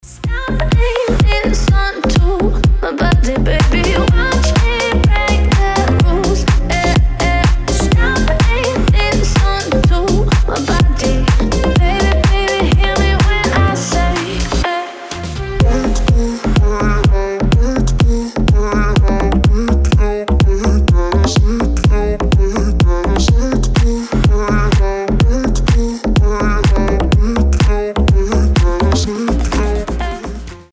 • Качество: 320, Stereo
женский вокал
deep house
Electronic
EDM